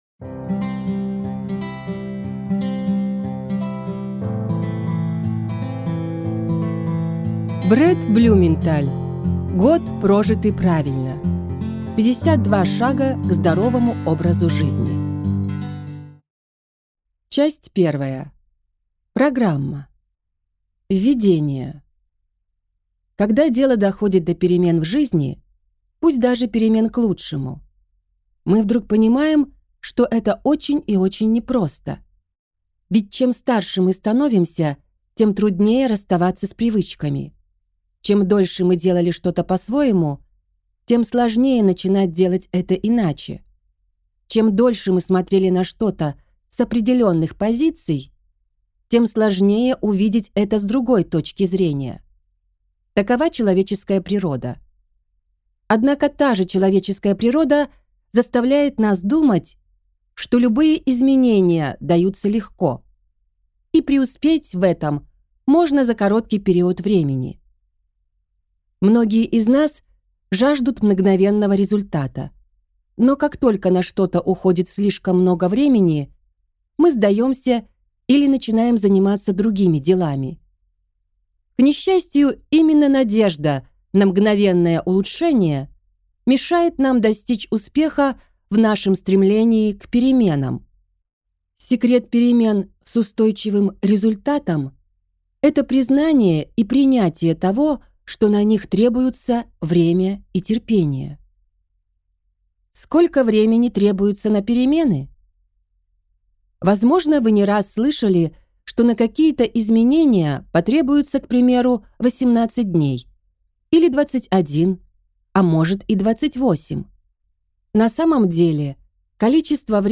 Аудиокнига Год, прожитый правильно: 52 шага к здоровому образу жизни | Библиотека аудиокниг